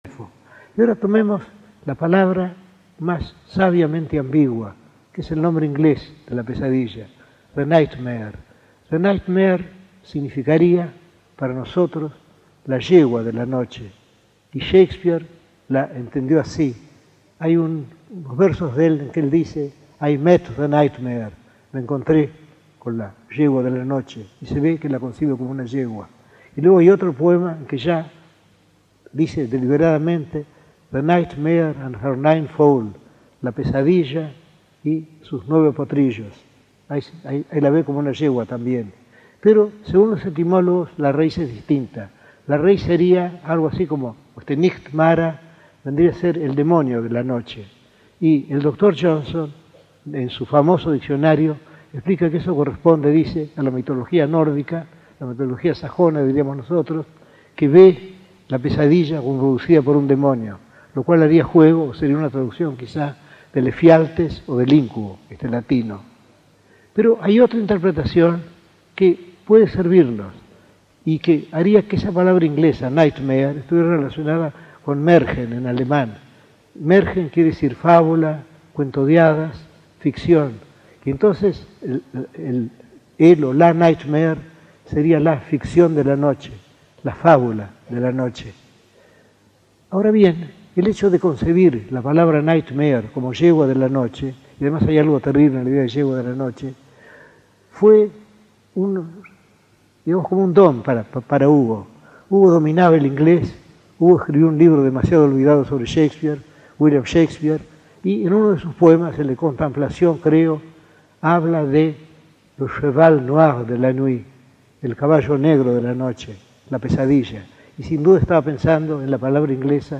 Conferencia en el Teatro Coliseo de BBAA, el 15 de junio de 1977